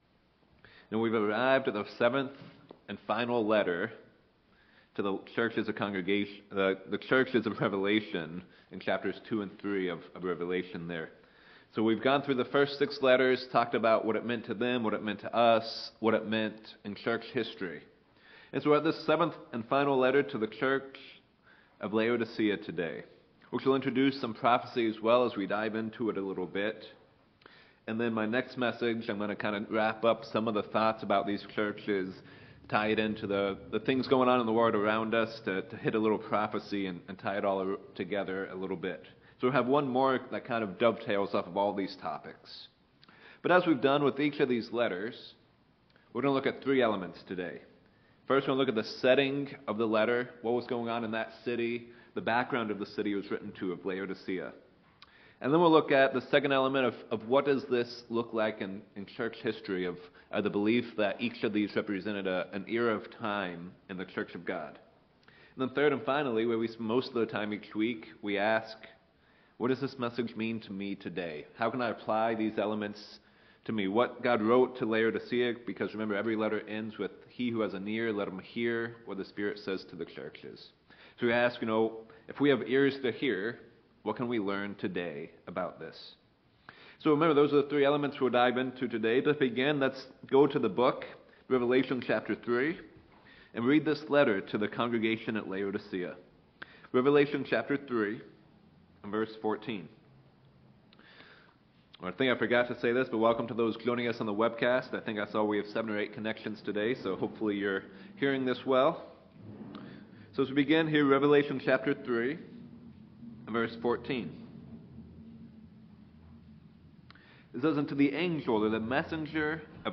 Sermons
Given in Reno, NV Sacramento, CA